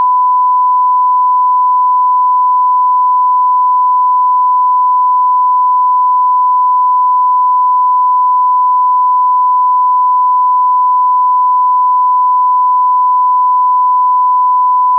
SmartAudio/8000-sine-1khz-stereo-s16_le-15s.wav at fd52e99587e8f15c28df951202b45d6693bd498a